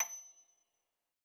53l-pno28-F6.wav